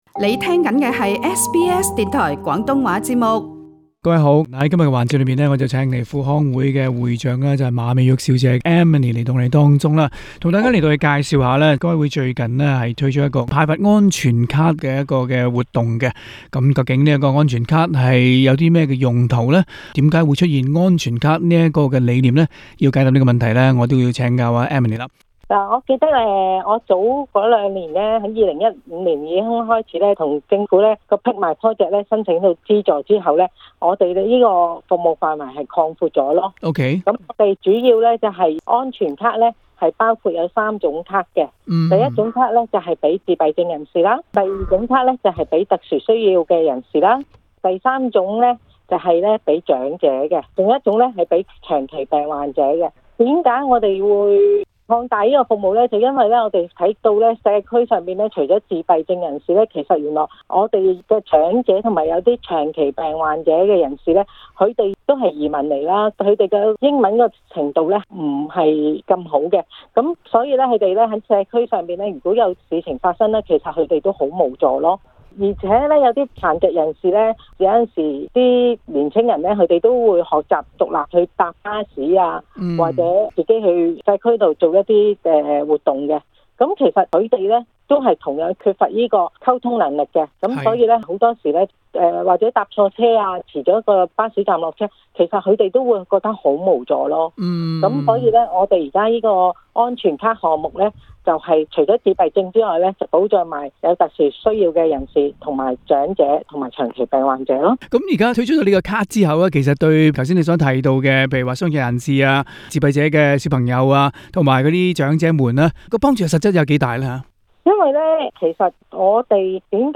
READ MORE 【國際自閉症關注周】新科技改善與自閉症孩子的溝通 【專訪】輔康會-華人父母工作坊 【社團訪問】 墨爾本輔康會即將舉行法律講座 瀏覽更多最新時事資訊，請登上 廣東話節目 Facebook 專頁 ， 或訂閱 廣東話節目Telegram頻道 。